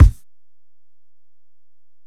Kick (18).wav